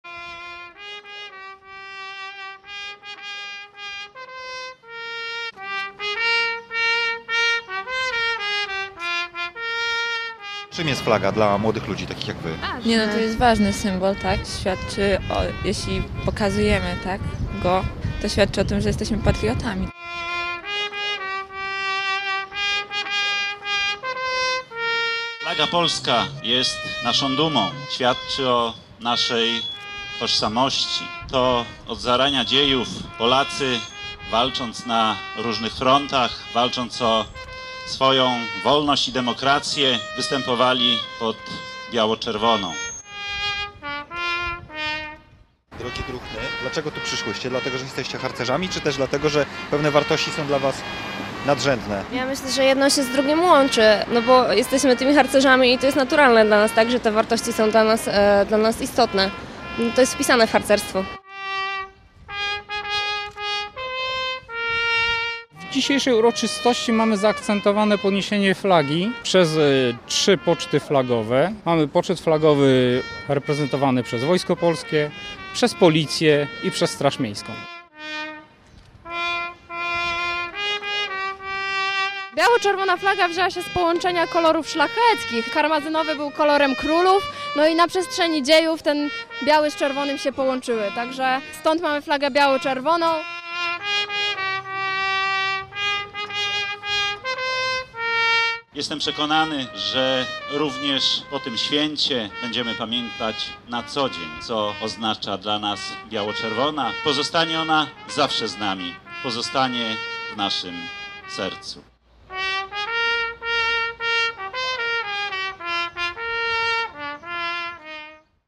W obecności pocztów sztandarowych pod olsztyńskim ratuszem odbyło się uroczyste spotkanie z okazji przypadającego dziś Dnia Flagi.